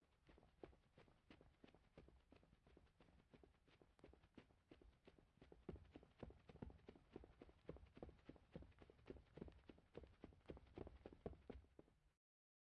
快速加慢速慢跑
描述：录制一个人在一个略微隔音的房间里用变焦H6缓慢而快速地慢跑。轻微的eq。
Tag: 快和慢 脚步声 WAV 慢跑 慢慢跑 跑步 步骤 快速 快慢跑 跑步 OWI 缓慢